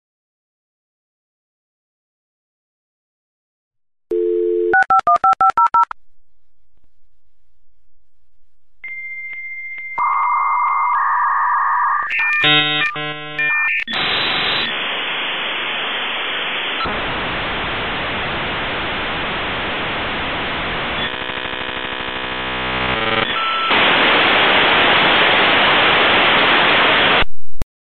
With Dialup going away, I can't help but bring this sound back to remind us of what used to connect us.